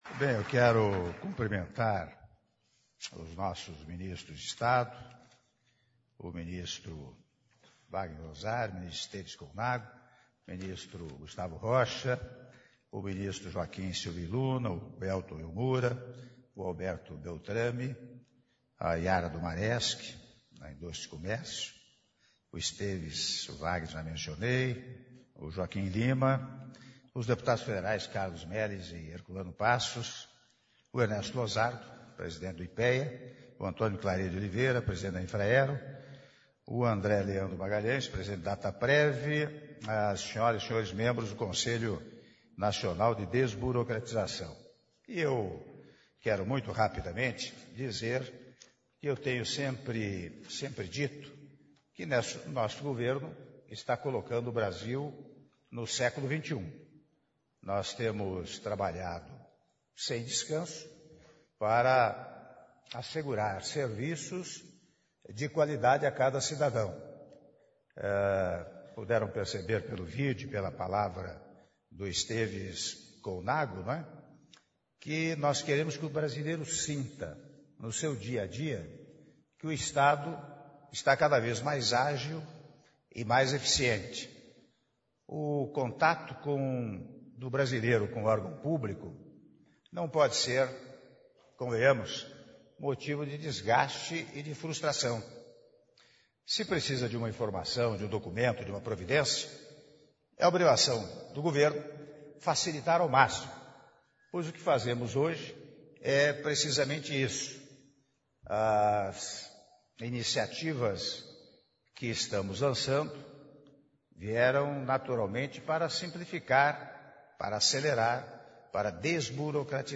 Áudio do discurso do Presidente da República, Michel Temer, durante a Cerimônia de Divulgação do Governo Digital: Rumo a Um Brasil Eficiente (07min13s)